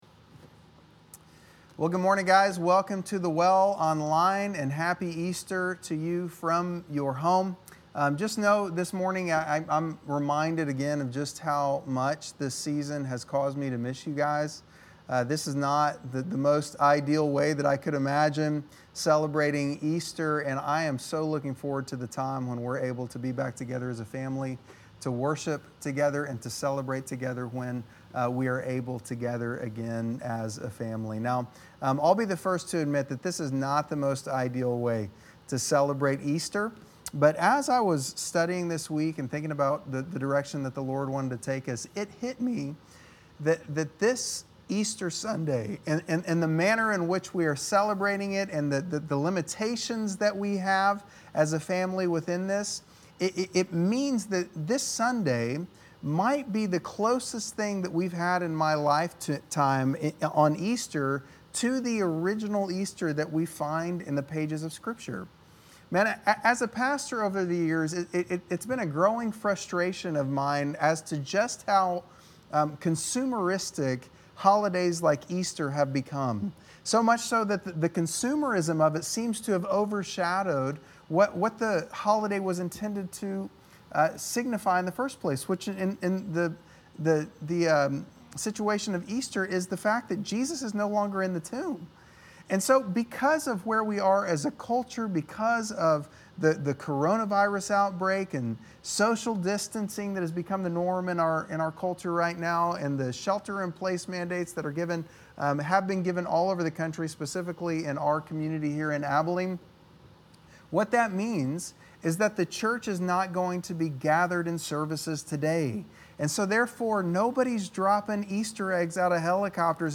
Easter-Message.mp3